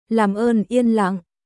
Làm ơn yên lặng.ラム オン イエン ランお願いだから静かにしてください